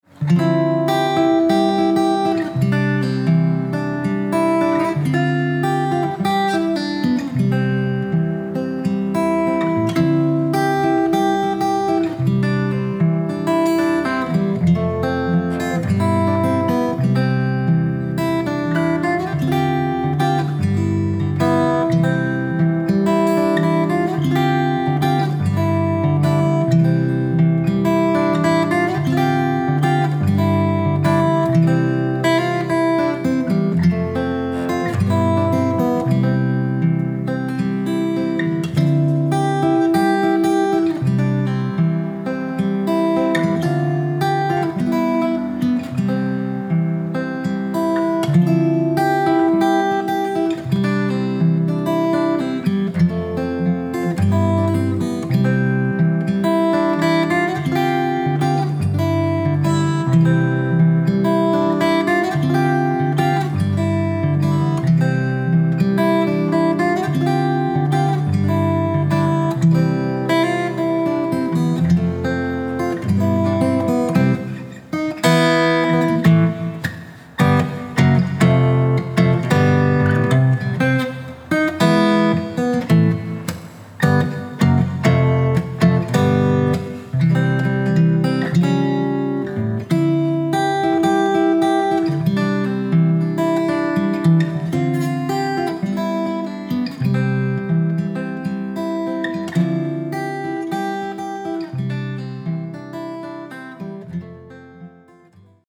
solo guitarist
beautiful instrumental arrangements of classic love songs